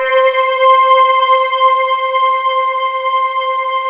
Index of /90_sSampleCDs/USB Soundscan vol.28 - Choir Acoustic & Synth [AKAI] 1CD/Partition D/22-RESOVOXAR